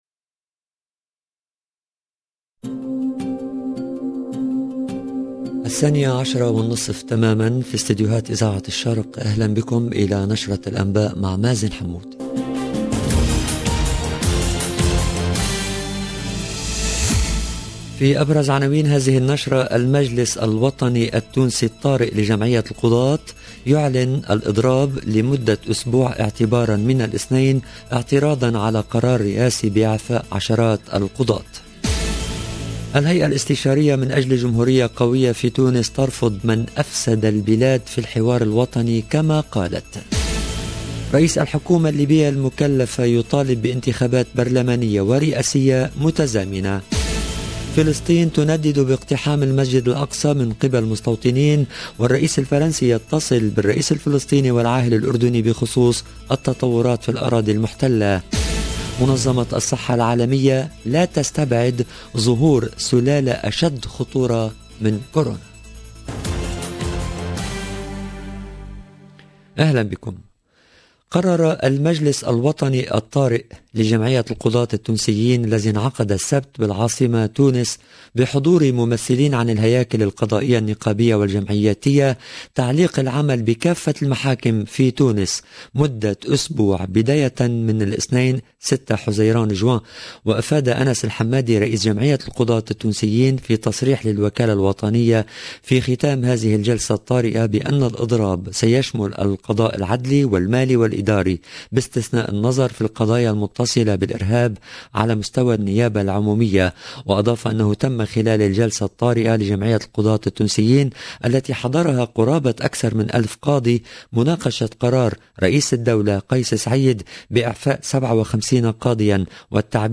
LE JOURNAL DE 12H30 EN LANGUE ARABE DU 5/6/2022